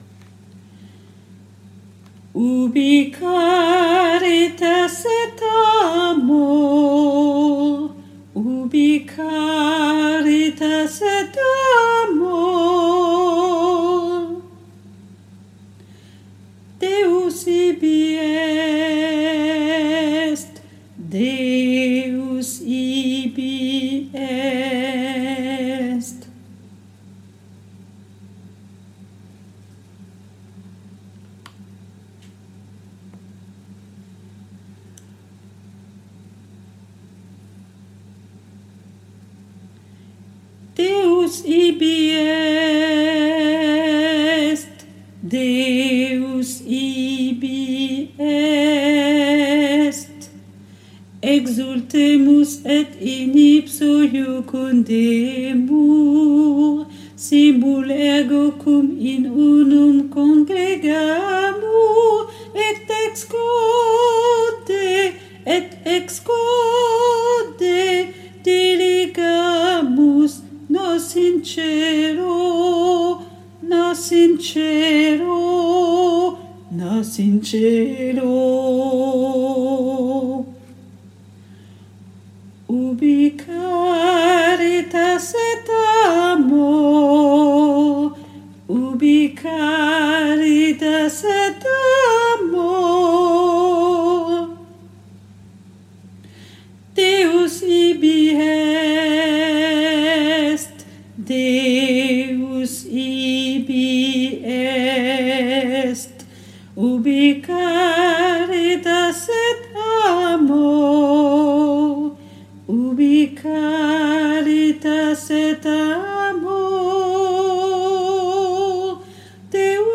MP3 versions chantées
Alto